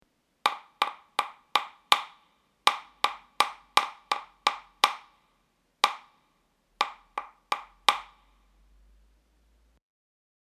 Imos practicar auditivamente o novo compás que estamos traballando: o 6/8.
Ditado rítmico:
dictado_ritmico.mp3